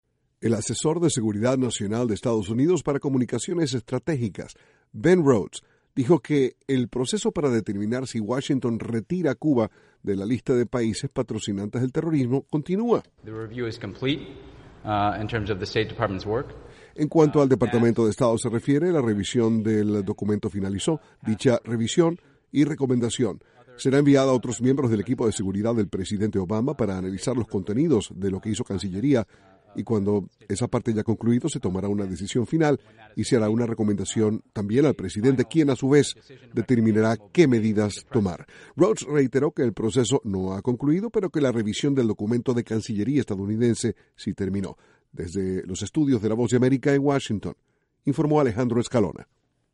Sigue el proceso por el cual Estados Unidos podría retirar a Cuba de la lista de países que patrocinan el terrorismo. Desde la Voz de América, en Washington, informa